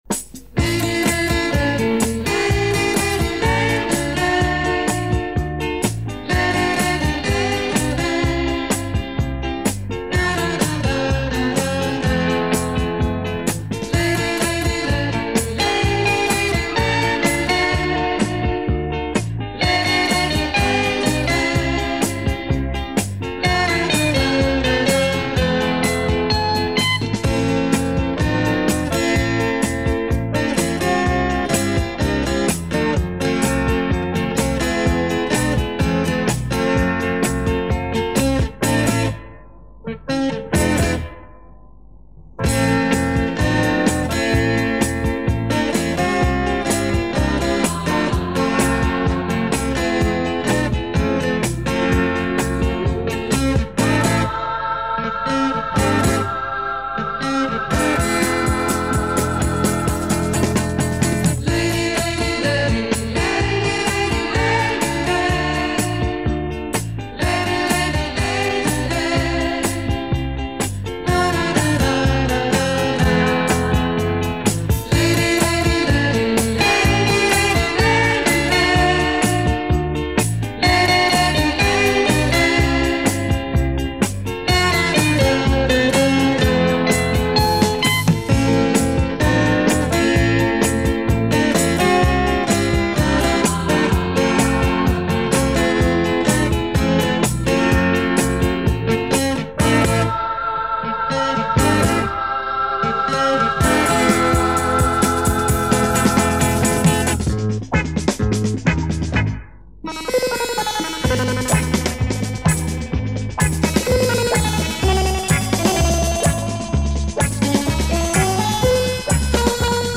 Гитарист